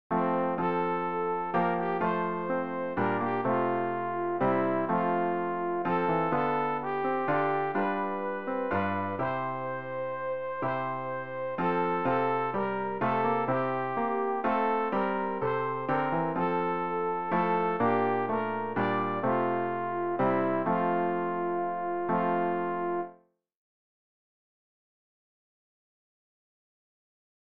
sopran-rg-018-der-herr-mein-hirte-fuehret-mich.mp3